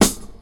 • Reggae Snare C Key 62.wav
Royality free snare sound tuned to the C note. Loudest frequency: 3425Hz
reggae-snare-c-key-62-r2J.wav